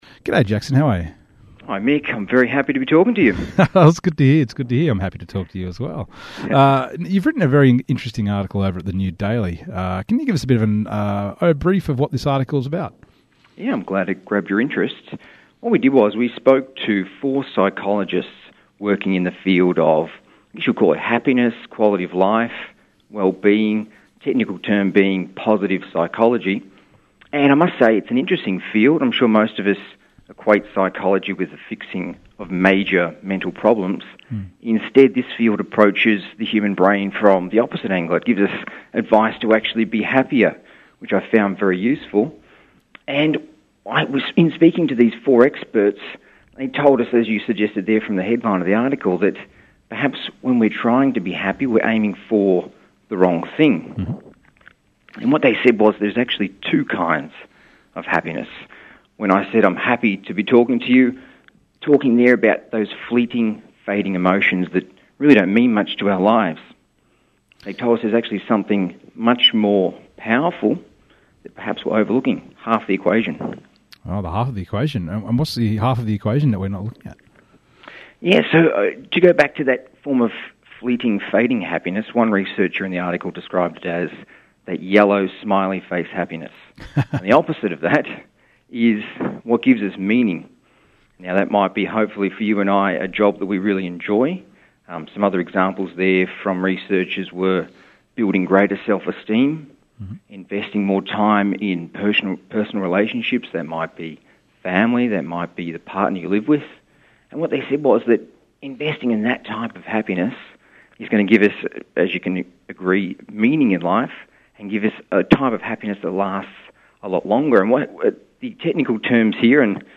AUDIO: The New Daily talks to radio statio 2ser 107.3 about this story